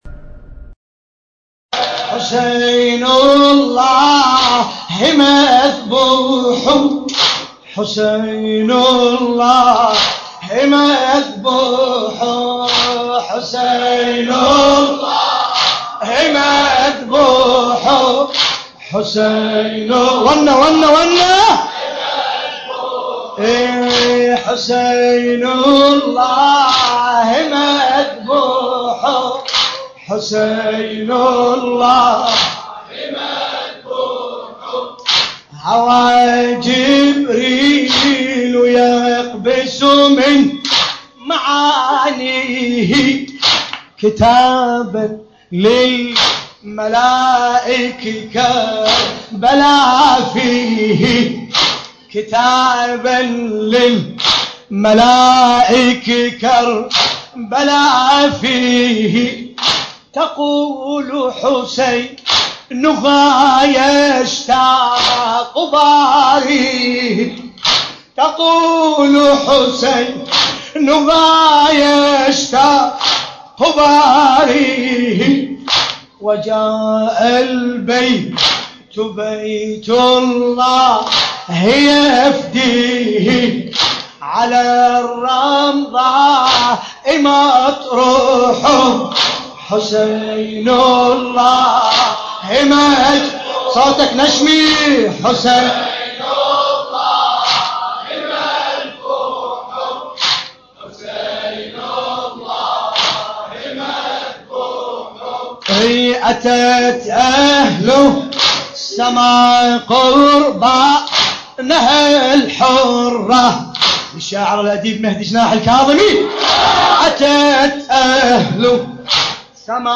القارئ: باسم الكربلائي التاريخ: الليلة الثانية من شهر محرم الحرام 1434 هـ - الكويت .